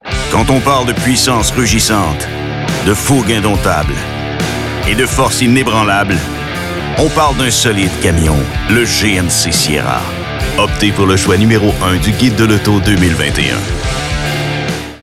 Diep, Vertrouwd, Volwassen
Corporate